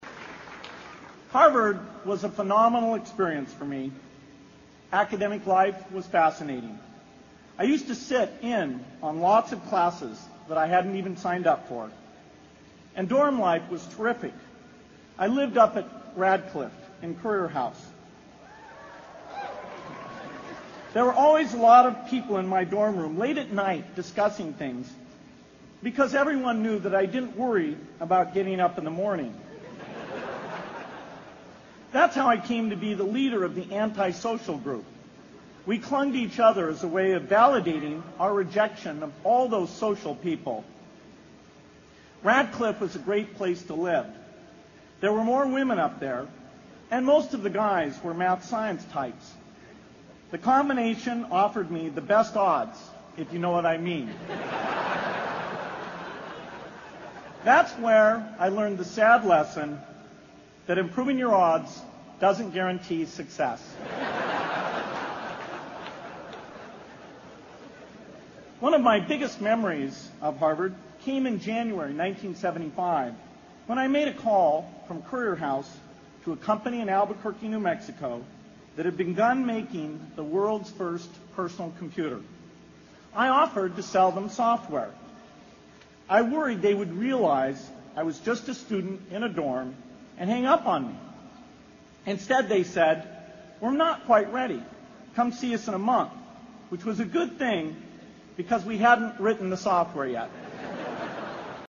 名校励志英语演讲 26:如何解决这个世界上最严重的不平等?
借音频听演讲，感受现场的气氛，聆听名人之声，感悟世界级人物送给大学毕业生的成功忠告。